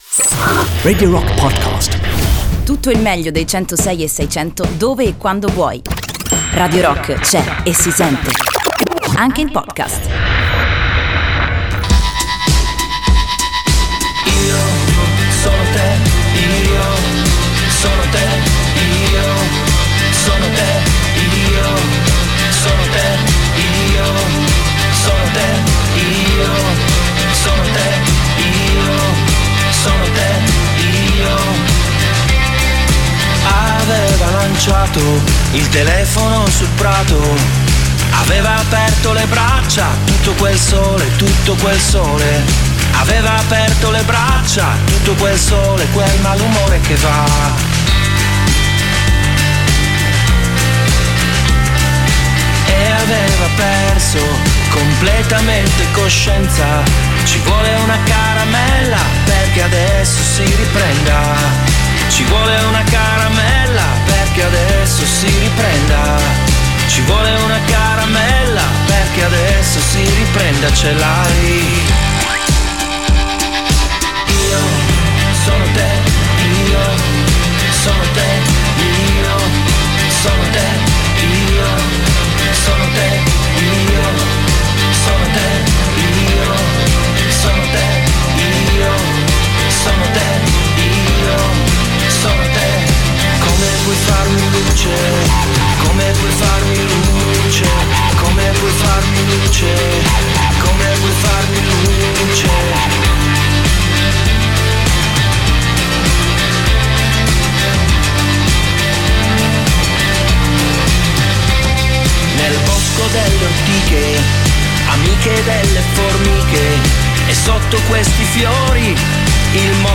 Intervista: Tre Allegri Ragazzi Morti - Sindacato dei sogni (28-01-19)